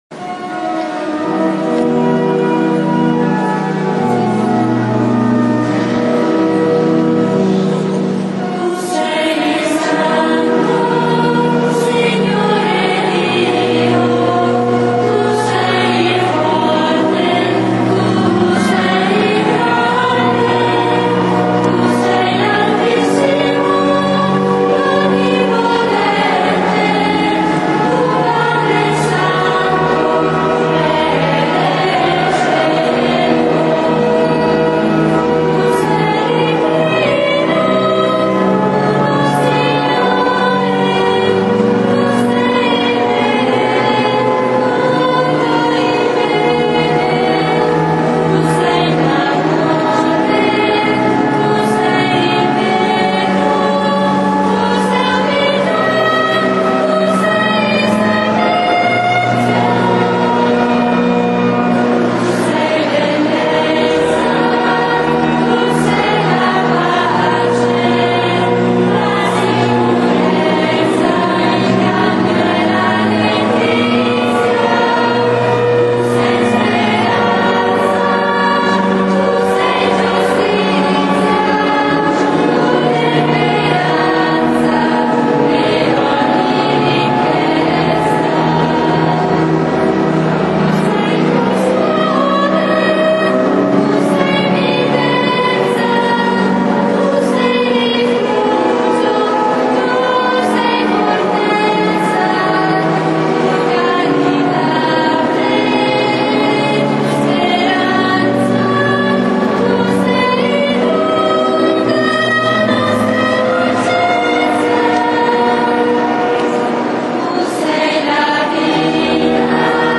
canto: Lodi all'Altissimo